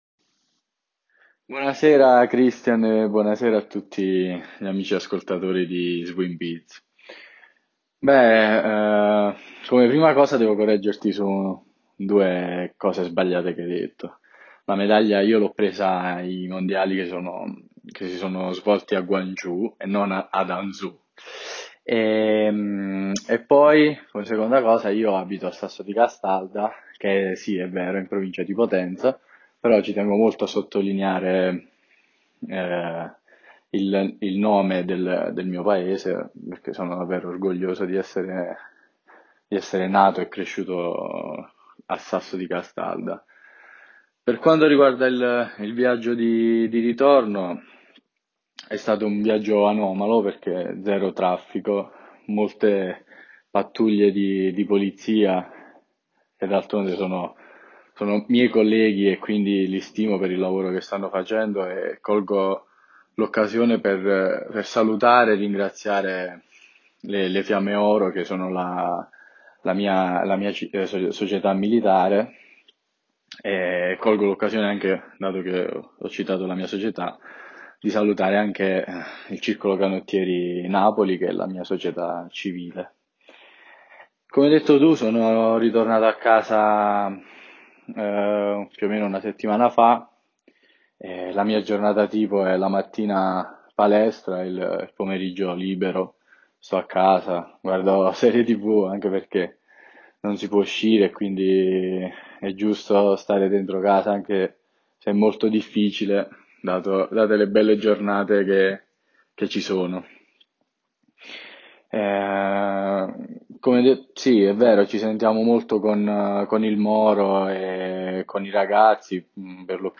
risposte-acerenza-swimbiz.mp3